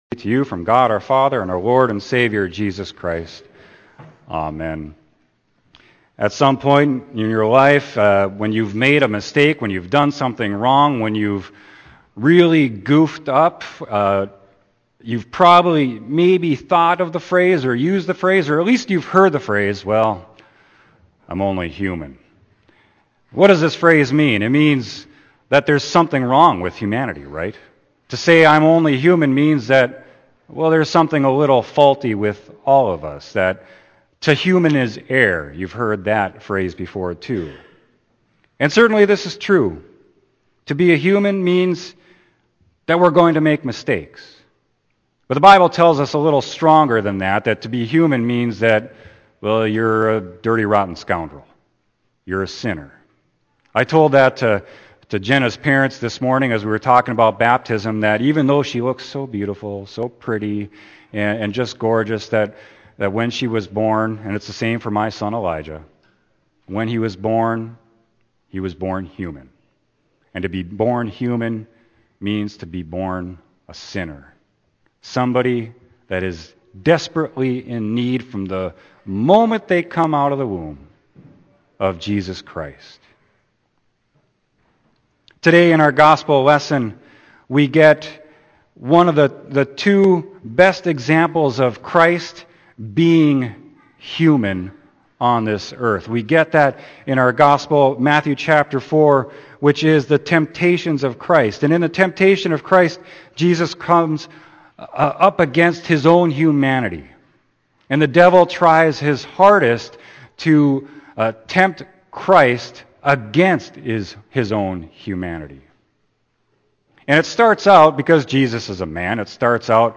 Sermon: Matthew 4.1-11